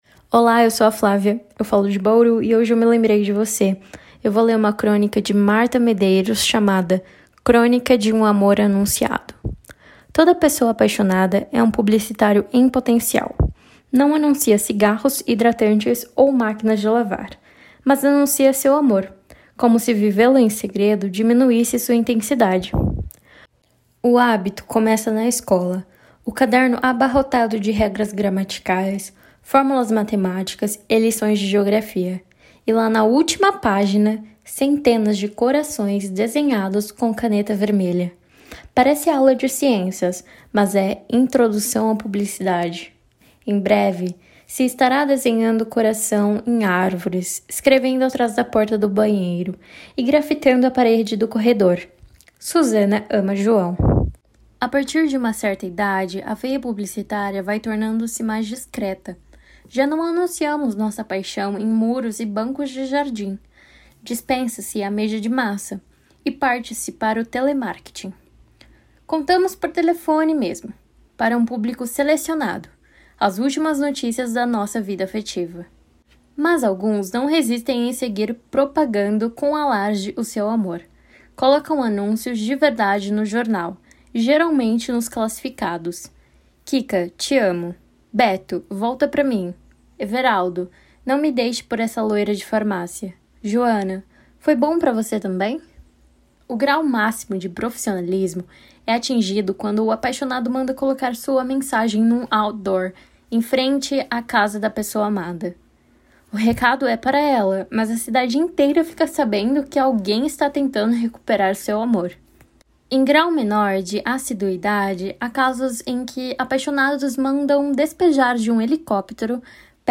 Crônica Português